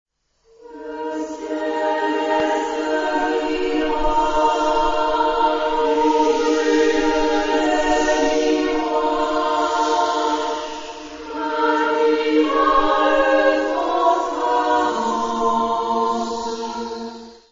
Genre-Style-Form: Secular ; Poem ; Contemporary
Mood of the piece: contemplative
Type of Choir: SMA  (3 women voices )
Tonality: modal